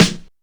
Deep Acoustic Snare Sample F Key 404.wav
Royality free snare one shot tuned to the F note.
deep-acoustic-snare-sample-f-key-404-ogU.mp3